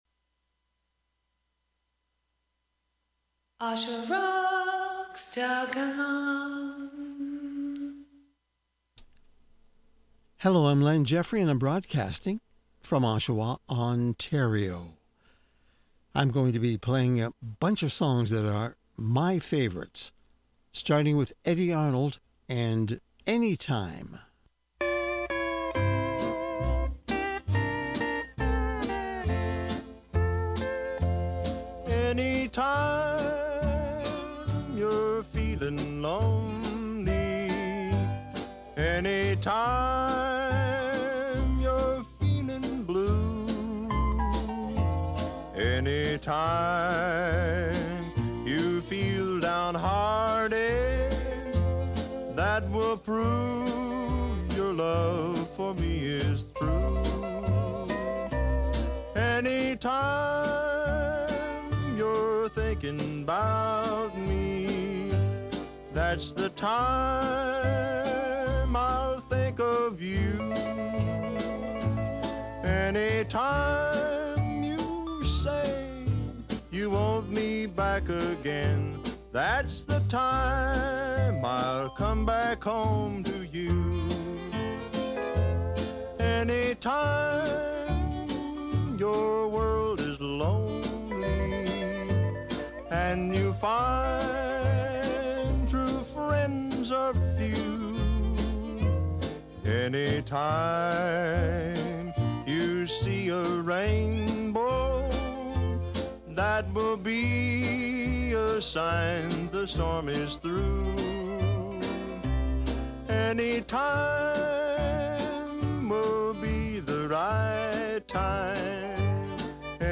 While I was doing this live I could see that there were no listeners but I forged onward.